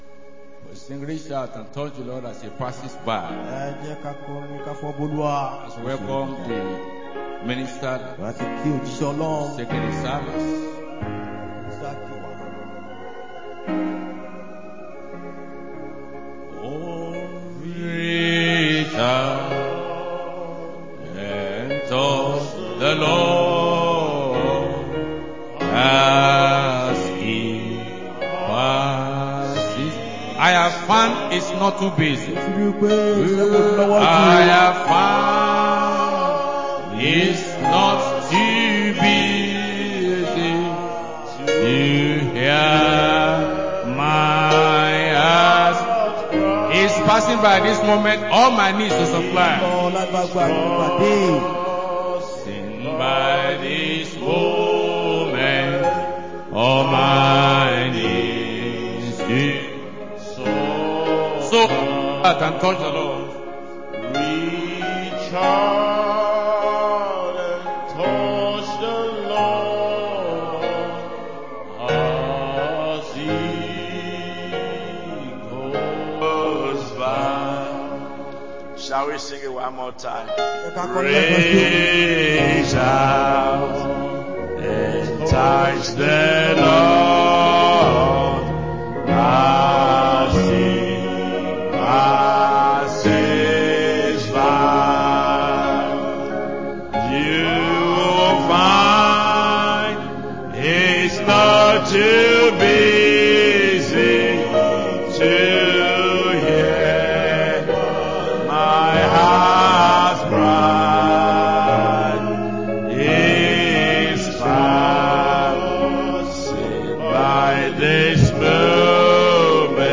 Sunday Main Service 21-09-25